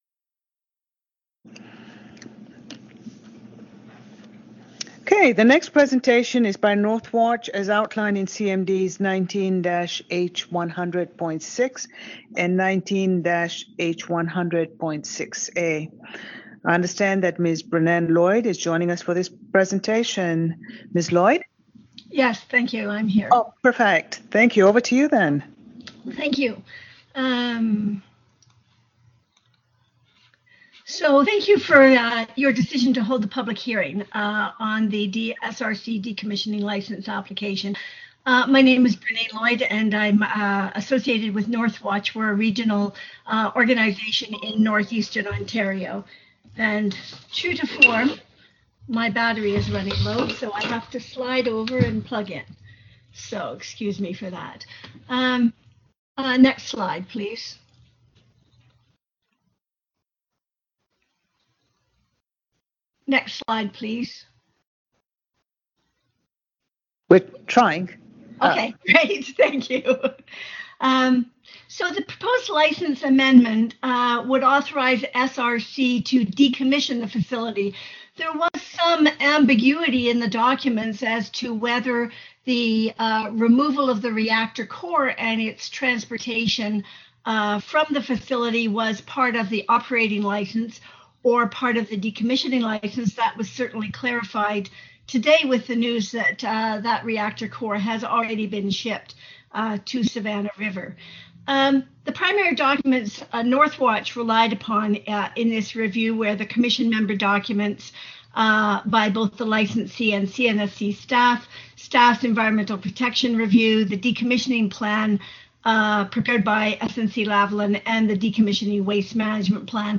Saskatchewan Research Council Request to authorize the decommissioning of the SLOWPOKE-2 reactor: Presentation by Northwatch (after break) 3.